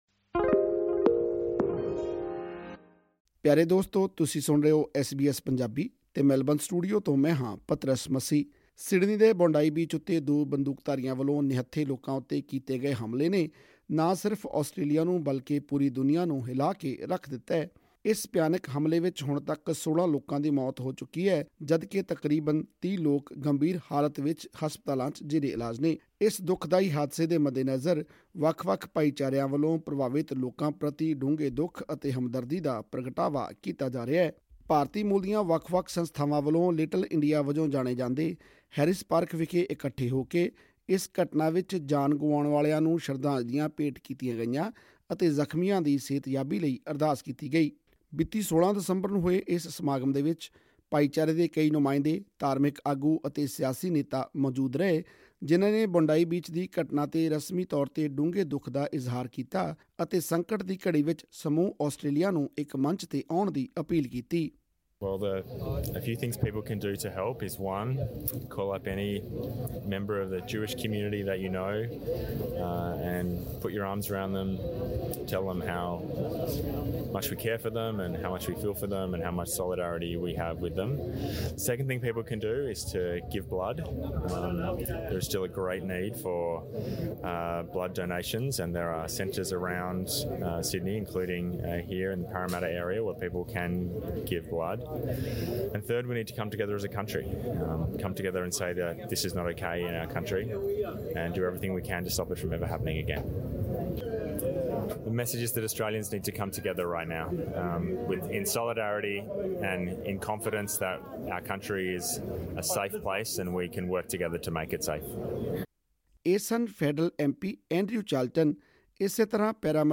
ਵੱਖ-ਵੱਖ ਬੁਲਾਰਿਆਂ ਨੇ ਇਸ ਸੰਕਟ ਦੀ ਘੜੀ ਵਿੱਚ ਸਮੂਹ ਆਸਟ੍ਰੇਲੀਆ ਨੂੰ ਇਕ ਮੰਚ ’ਤੇ ਆੳੇੁਣ ਦਾ ਸੱਦਾ ਦਿੱਤਾ ਅਤੇ ਸਰਕਾਰ ਨੂੰ ਅਪੀਲ ਕੀਤੀ ਕਿ ਅਜਿਹੀਆਂ ਘਟਨਾਵਾਂ ਨੂੰ ਰੋਕਣ ਲਈ ਸਖ਼ਤ ਕਦਮ ਚੁੱਕੇ ਜਾਣ। ਹੋਰ ਵੇਰਵੇ ਲਈ ਸੁਣੋ ਇਹ ਰਿਪੋਰਟ,,,,